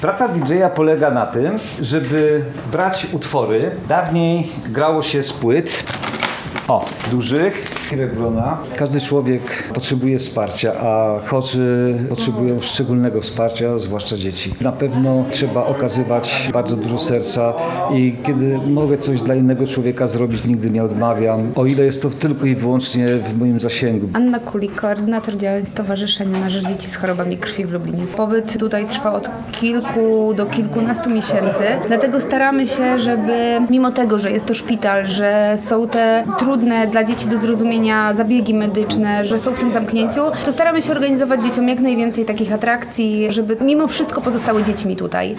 Zwłaszcza dzieci – mówi w rozmowie z Radiem Lublin Hirek Wrona.